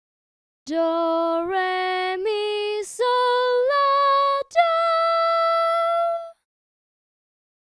Solfa Scale for Melody 3
Ex-3c-solfa-scale.mp3